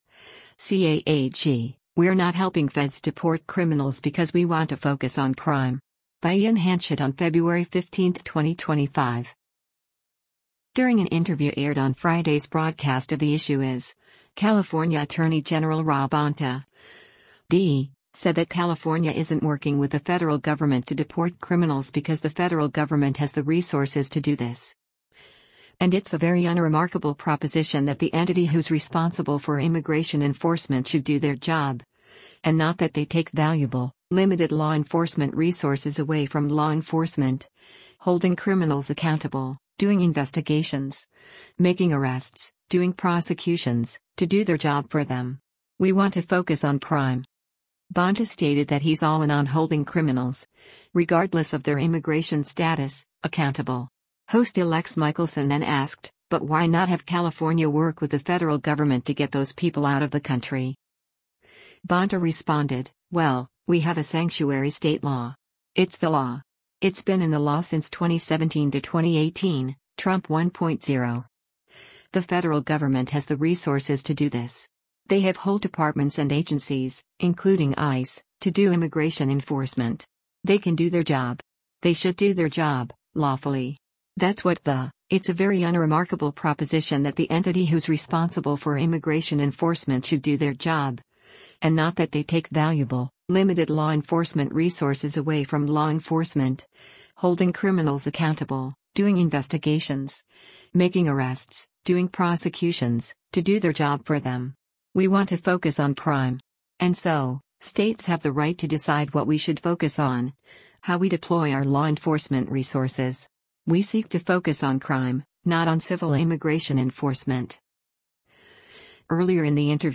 During an interview aired on Friday’s broadcast of “The Issue Is,” California Attorney General Rob Bonta (D) said that California isn’t working with the federal government to deport criminals because “The federal government has the resources to do this.”